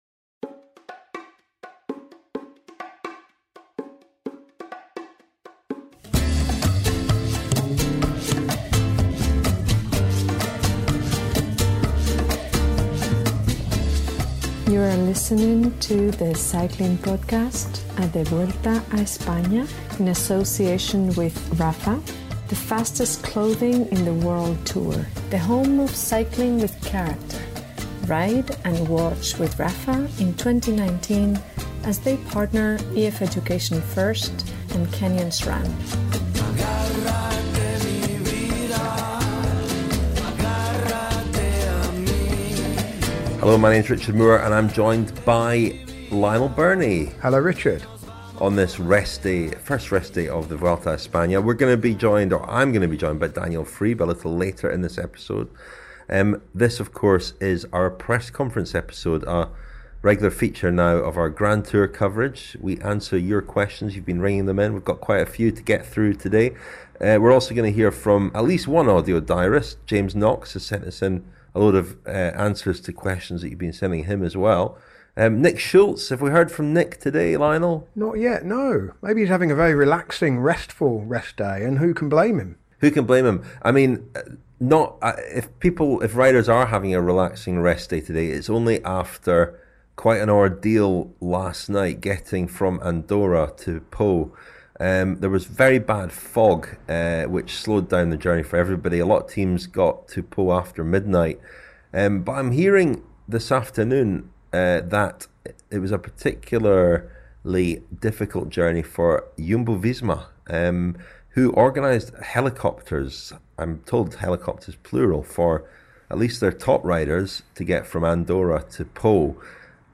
We also hear from our Vuelta diarists